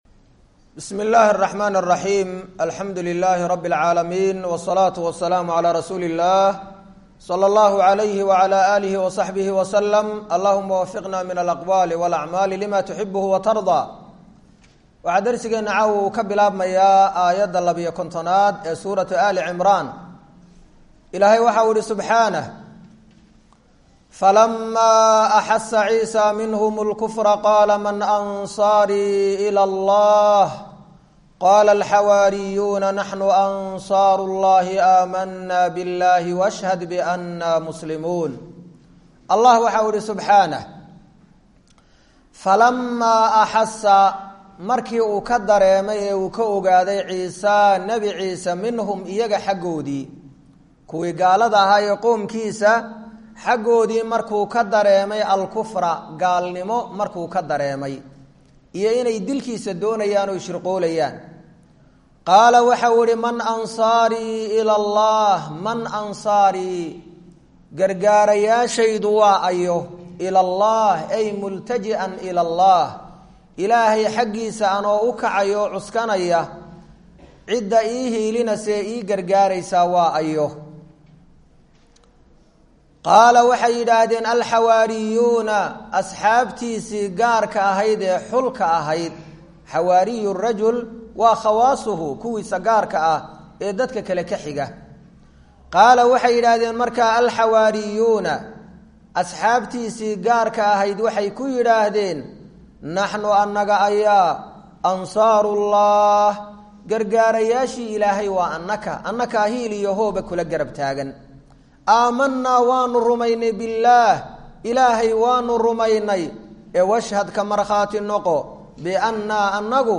Waa Tafsiirka Qur’aanka Ee Ka Socda Masjid Ar-Rashiid – Hargaisa